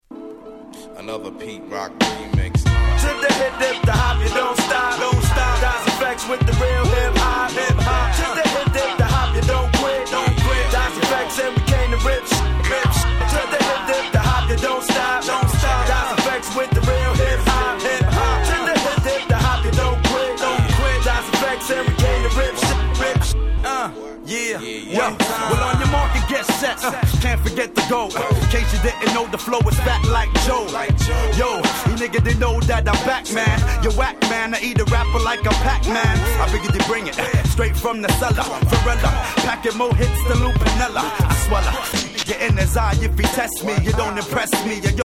【Media】Vinyl 12'' Single (Promo)